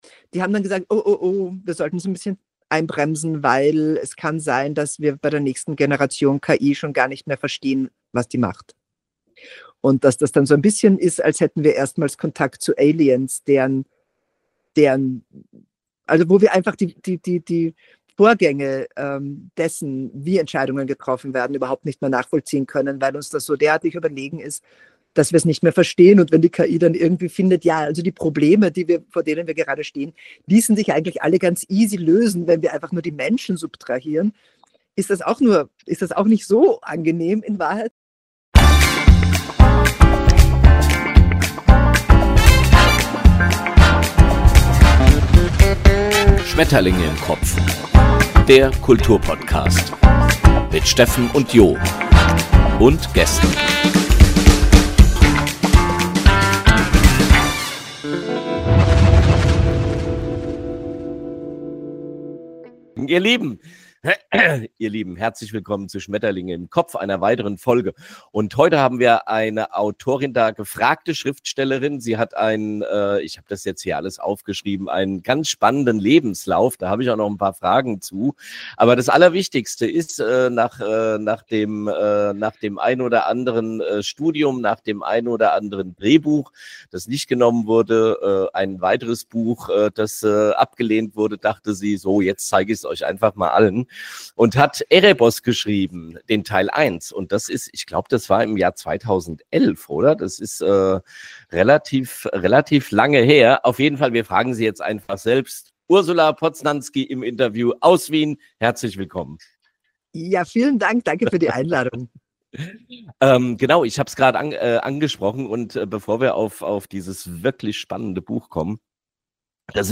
Ähnlich spannend ist auch Ursula Poznanski, die schon viel im Leben gemacht hat und mit uns sehr entspannt darüber plaudert.